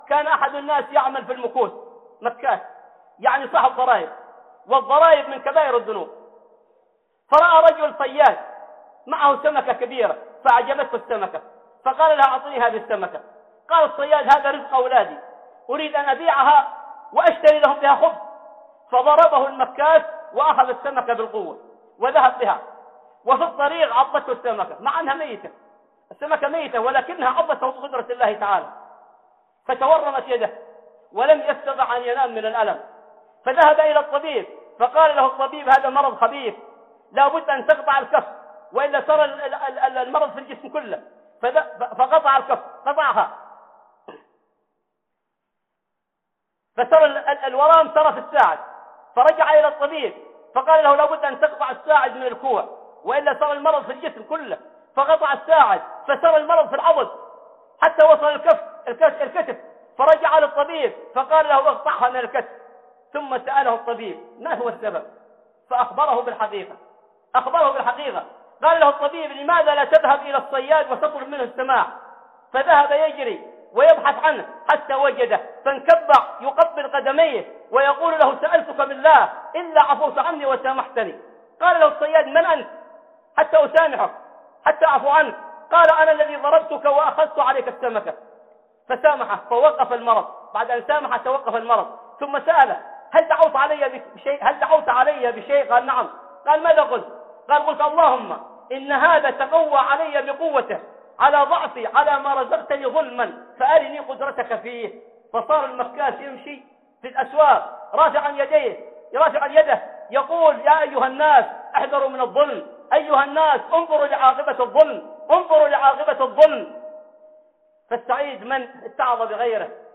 دعوة المظلوم - خطب